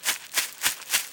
run.wav